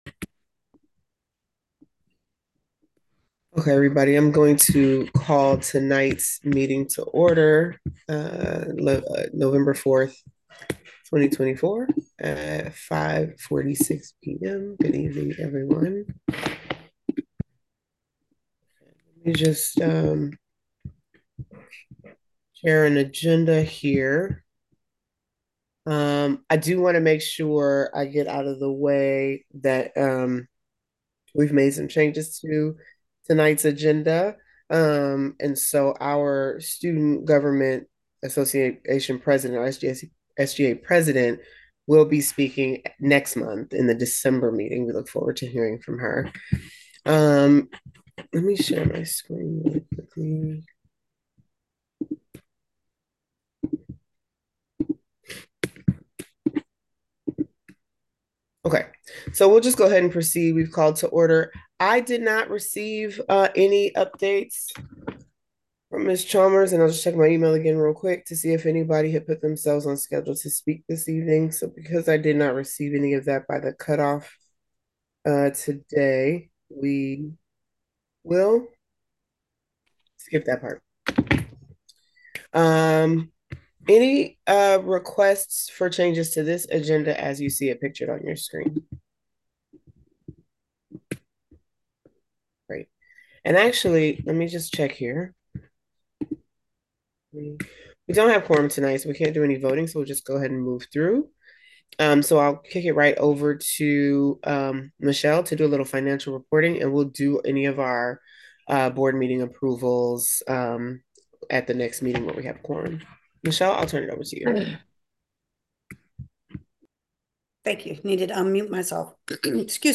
11_4_24_GOWILM-BOARD-MEETING-AUDIO.m4a